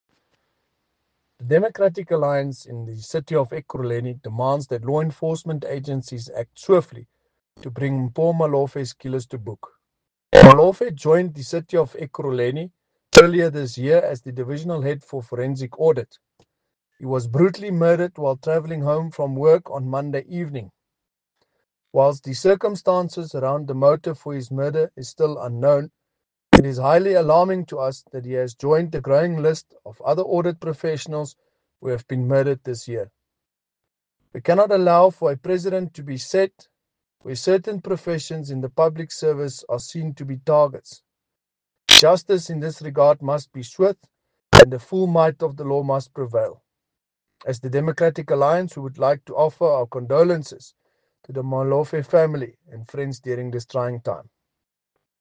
Note to Editors: Please find English and Afrikaans soundbites by Cllr Brandon Pretorius